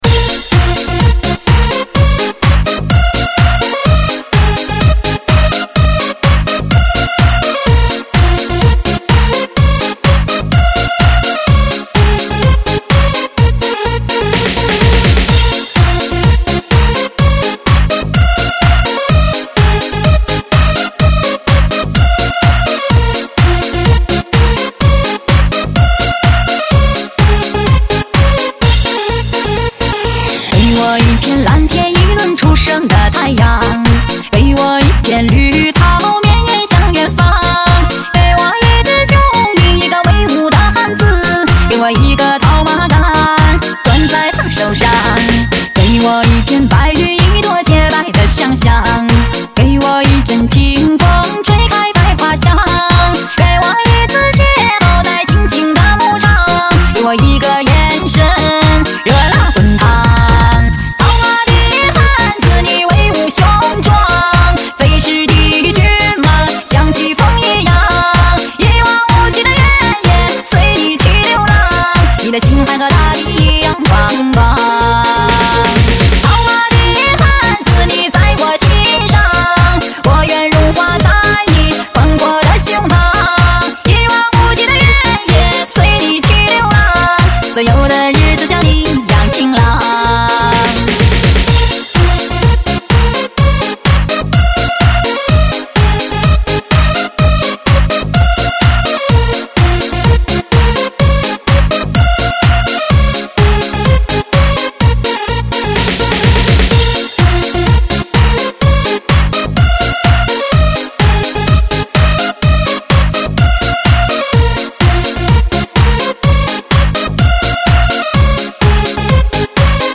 数位录音 环绕音效 雷射跳动鼓 飚车嗨曲